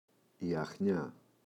αχνιά, η [a’xɲa]